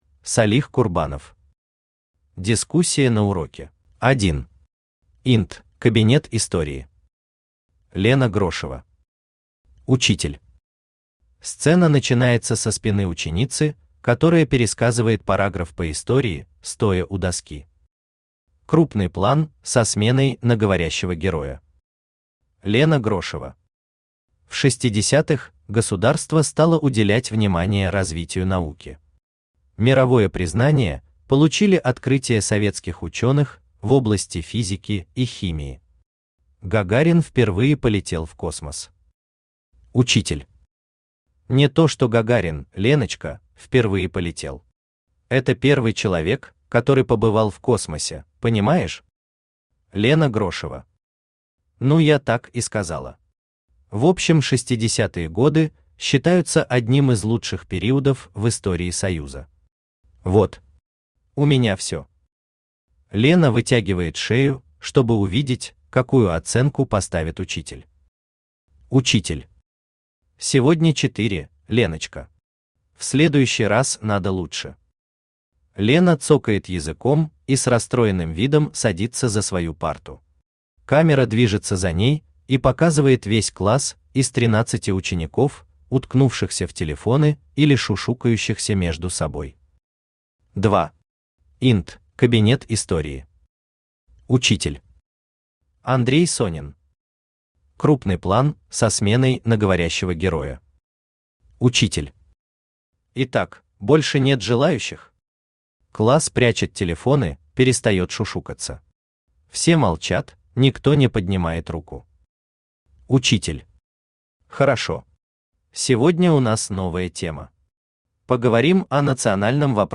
Aудиокнига Дискуссия на уроке Автор Салих Магомедович Курбанов Читает аудиокнигу Авточтец ЛитРес.